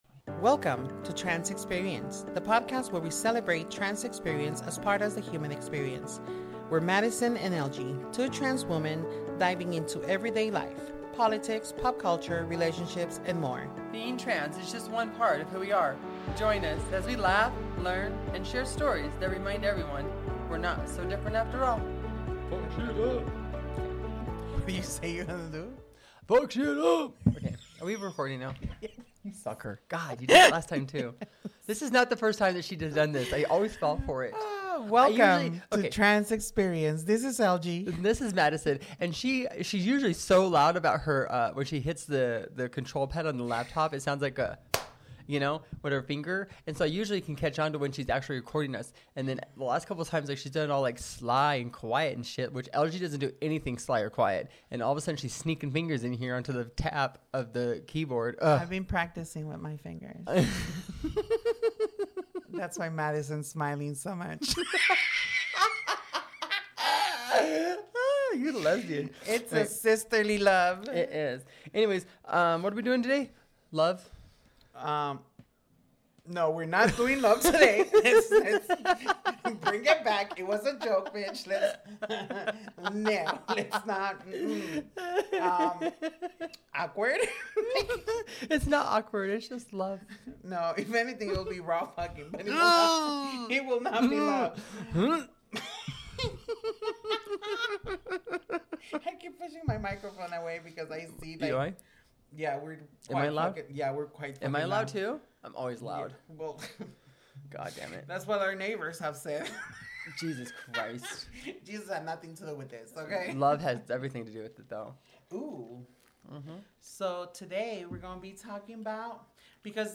Our girls have a healthy co versation about love, and express their different views, and ideas on the topic